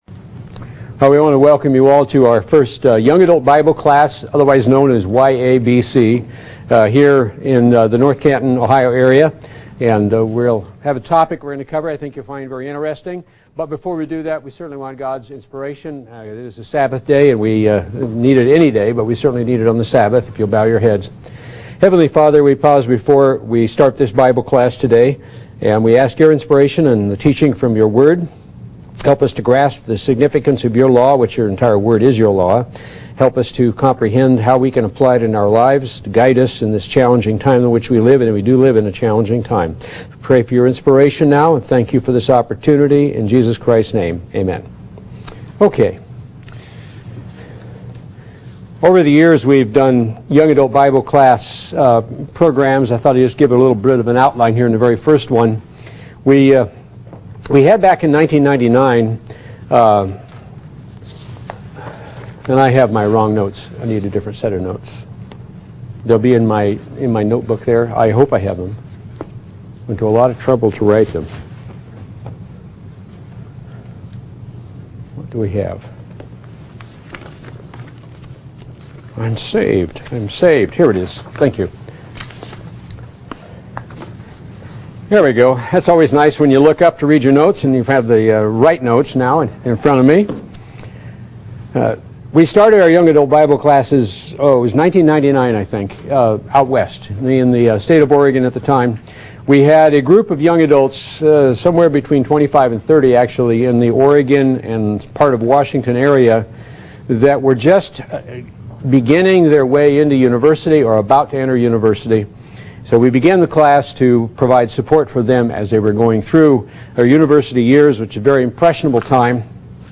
Young Adult Bible Study: Who Are You?
Given in North Canton, OH